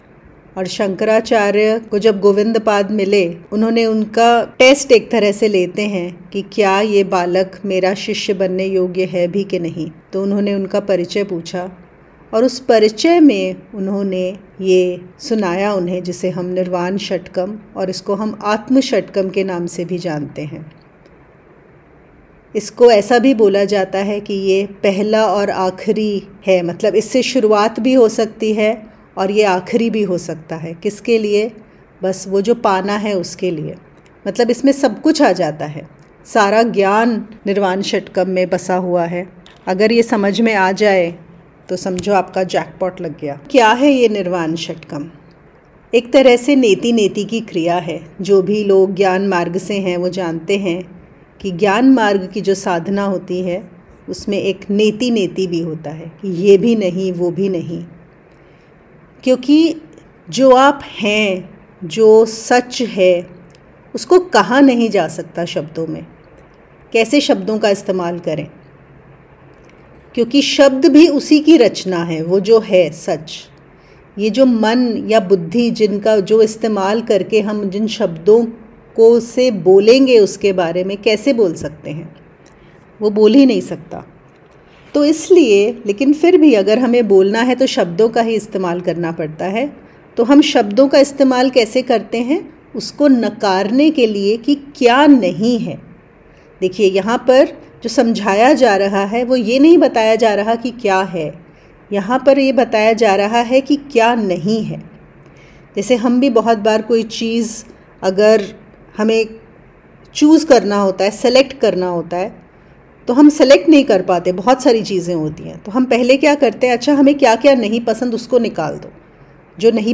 Talks on the topics of spirituality, nondualism, advaita, vedanta, path of knowledge, other spiritual paths etc. for spiritual seekers.